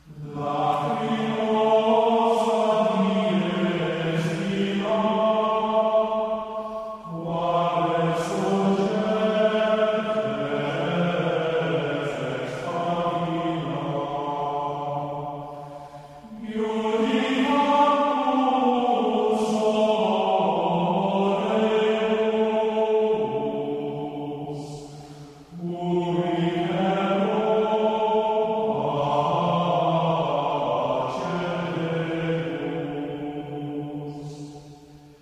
So, here, for your geeky pleasure is a Gregorian Chant ringtone for the iPhone.
gregorian_ring_1.mp3